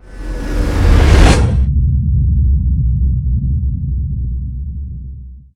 BorgExitWarp.wav